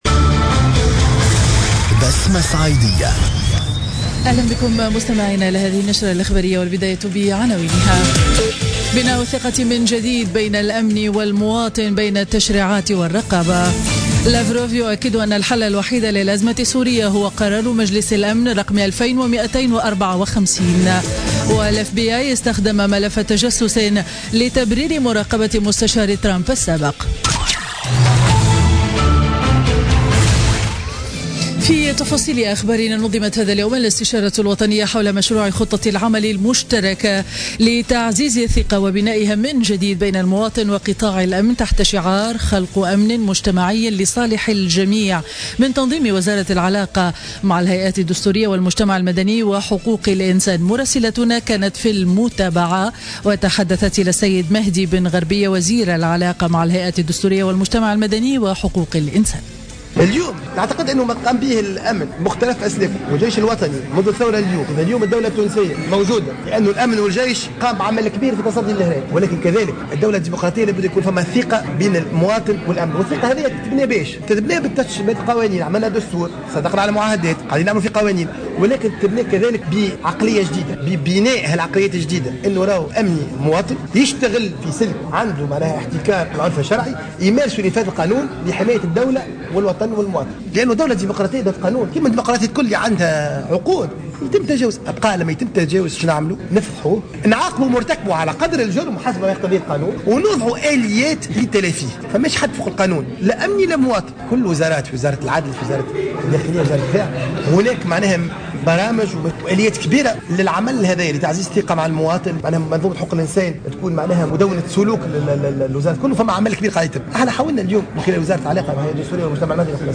نشرة أخبار منتصف النهار ليوم الإربعاء 19 أفريل 2017